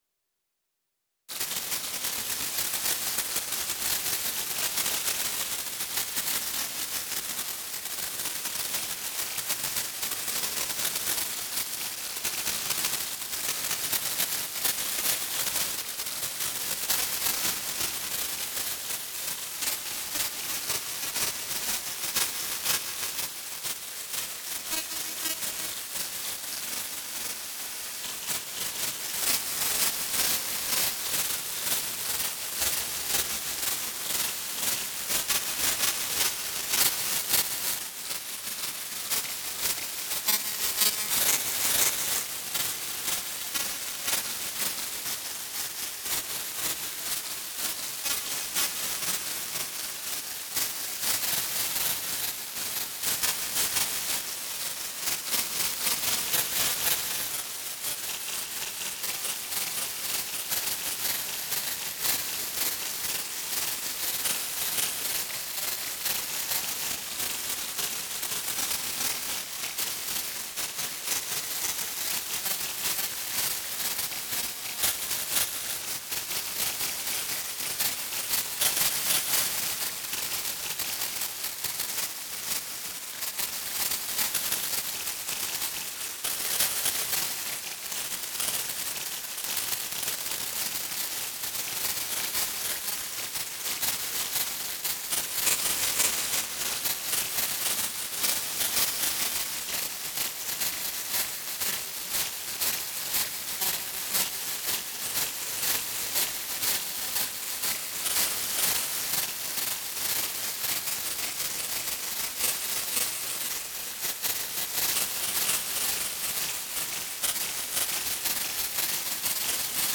ResyNoiseDrum.mp3